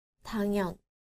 • 당연
• dangyeon